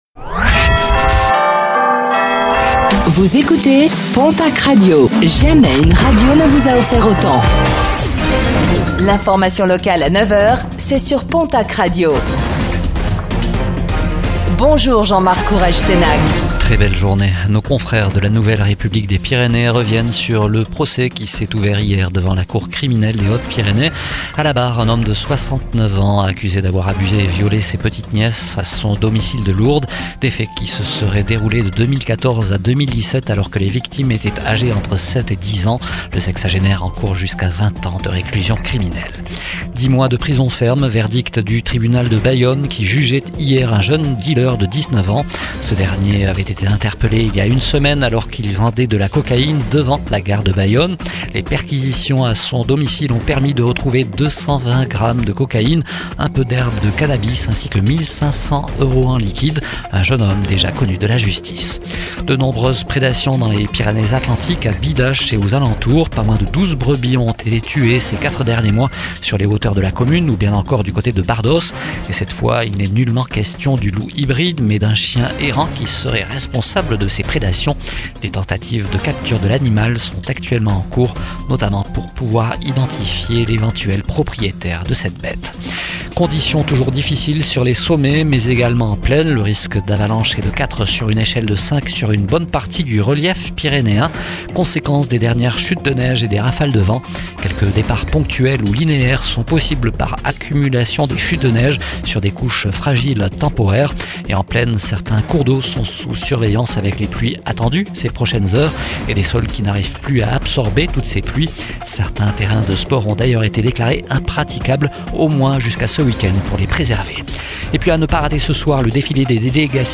Infos | Mardi 27 janvier 2026